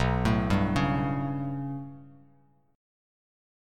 B7sus2#5 chord